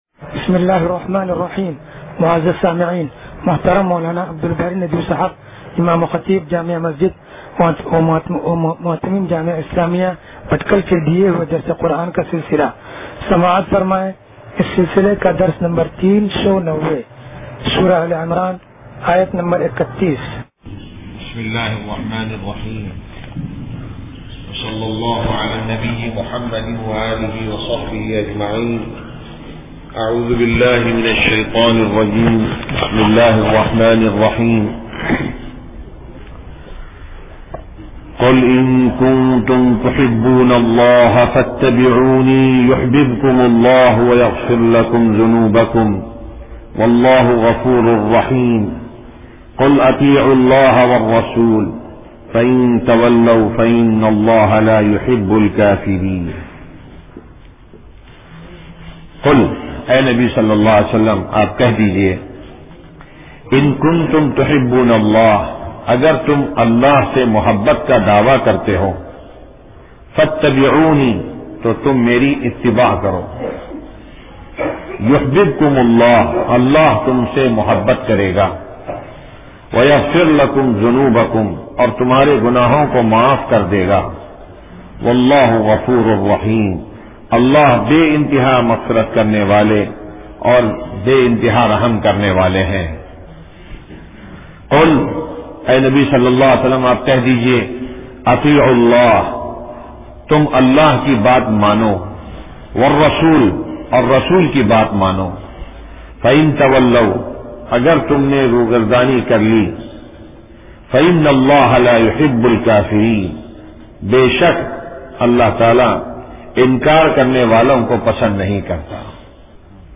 درس قرآن نمبر 0390